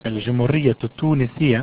Arabic: (OFFICIAL:) 'al-jumhūriyyatu t-tūnisiyya